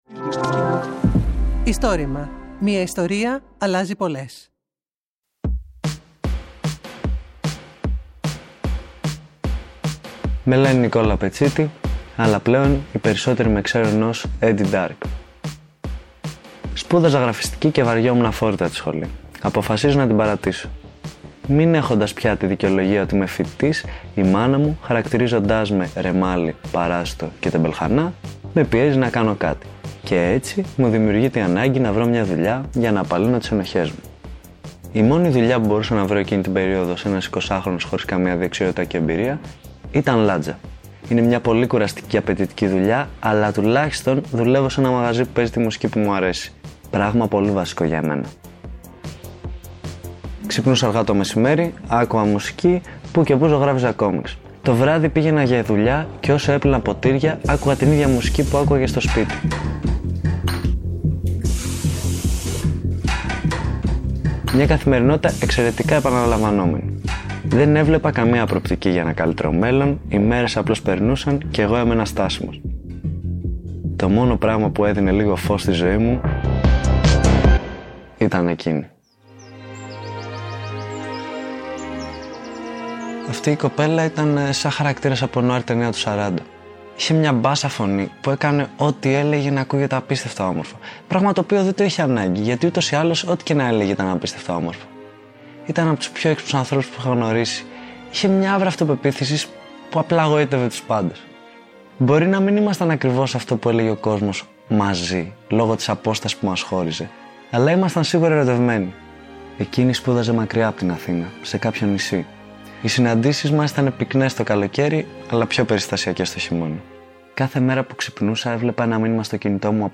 Συνέντευξη
Το Istorima είναι το μεγαλύτερο έργο καταγραφής και διάσωσης προφορικών ιστοριών της Ελλάδας.